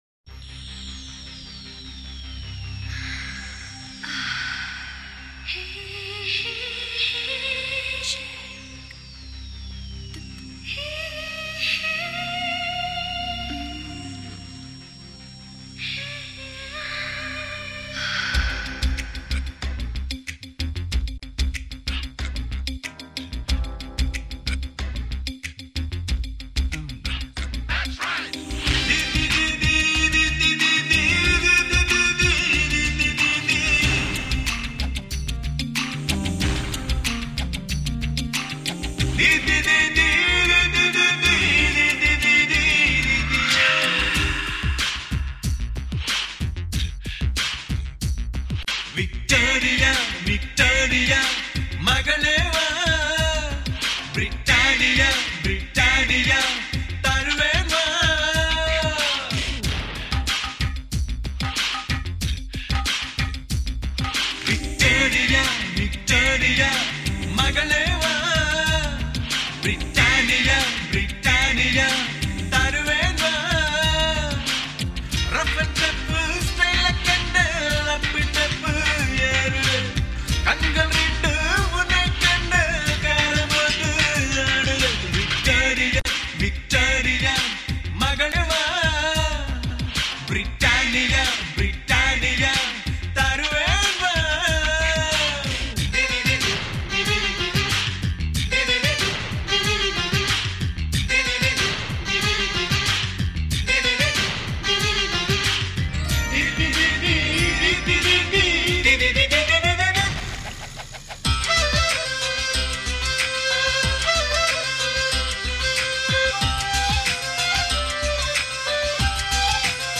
Tamil Movie Songs